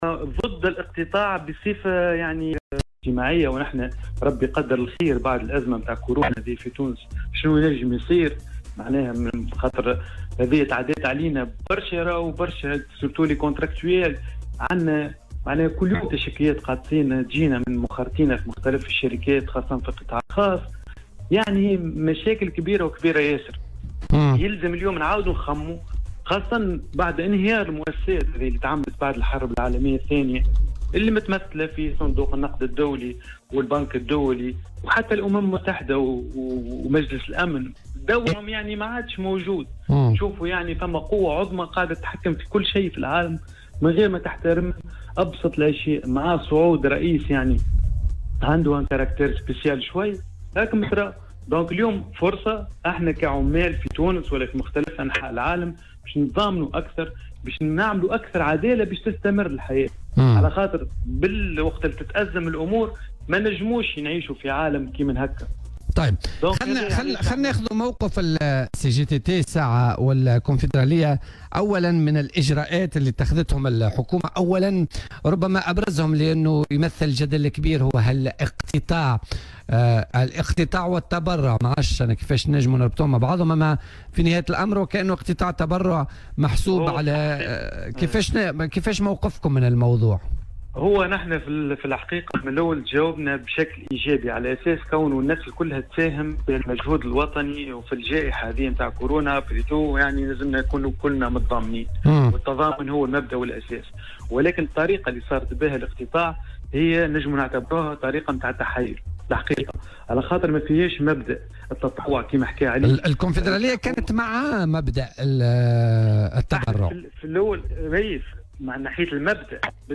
وأضاف في مداخلة له اليوم في برنامج "بوليتيكا" أن المعمول به هو أن يتم الاقتطاع من أجرة شهر وليس من الدخل السنوي الذي يشمل المنح، مشيرا إلى أن عملية الاقتطاع كلّفت بعض القطاعات يومي عمل أو أكثر.